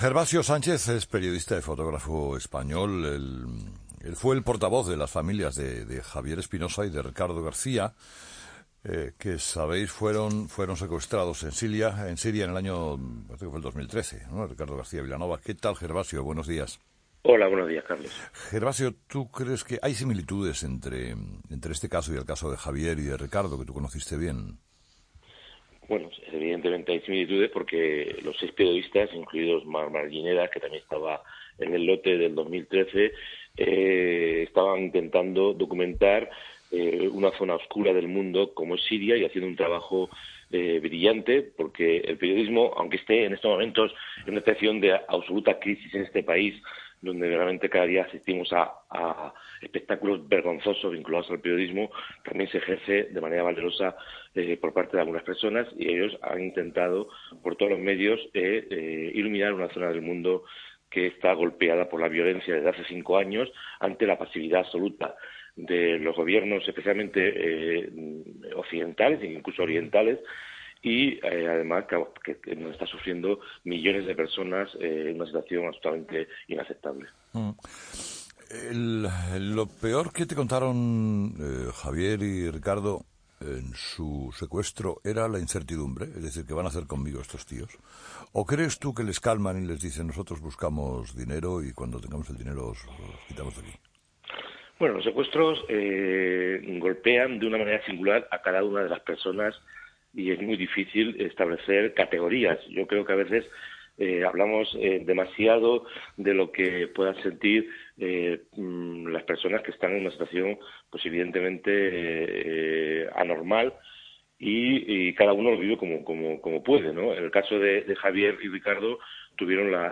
Entrevista con Gervasio Sánchez